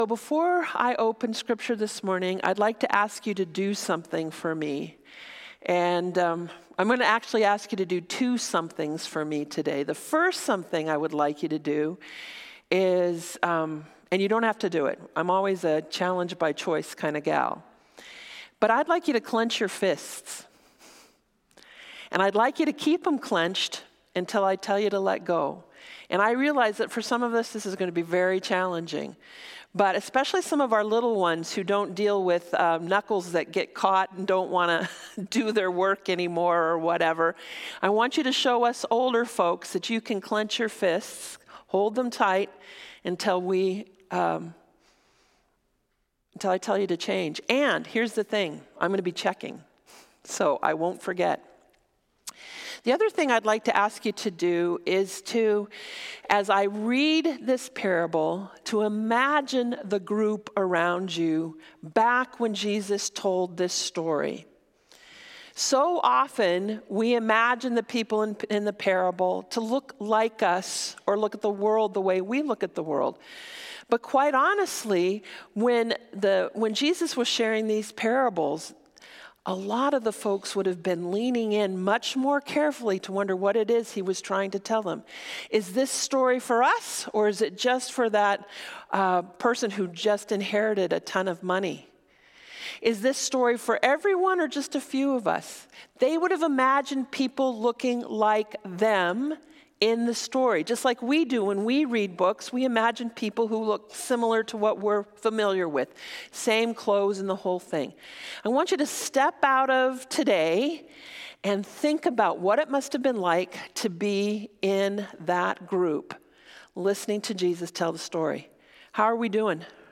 Sermons | Community Christian Reformed Church
Guest Preacher